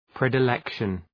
Προφορά
{,predı’lekʃən}